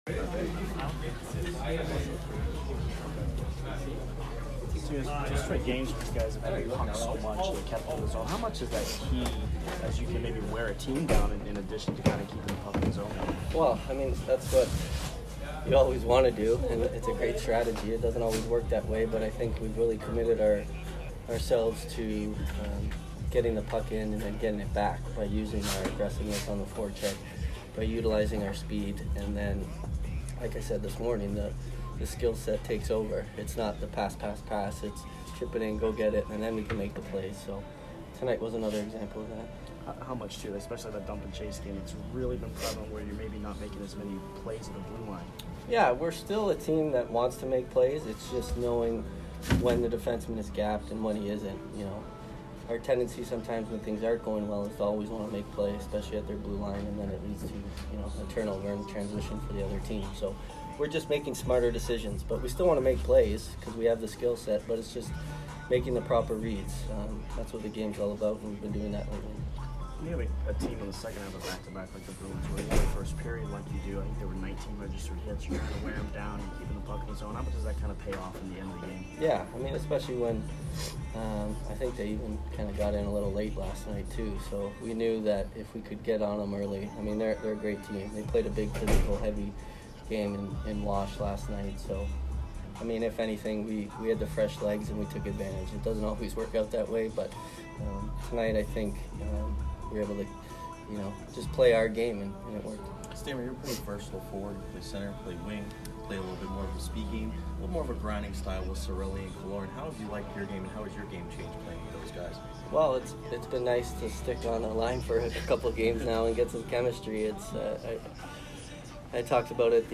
Stamkos post-game 12/12